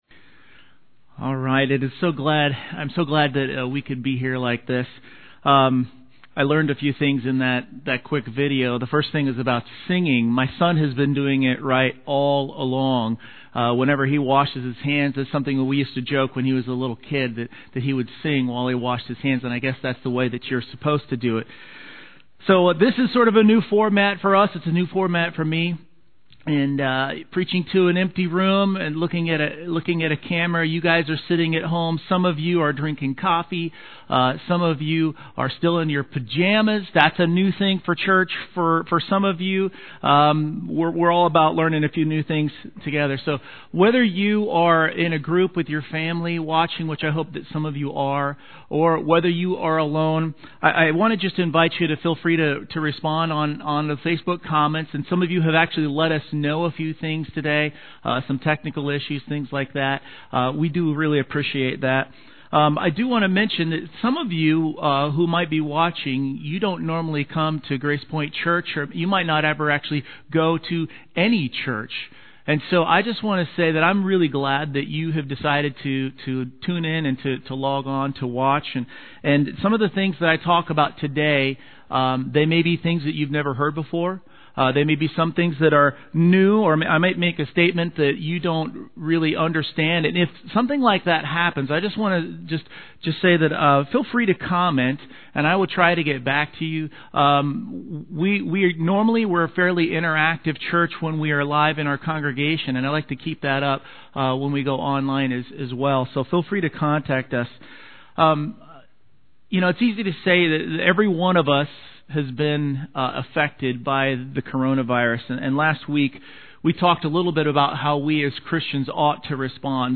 Series: 2020 Sermons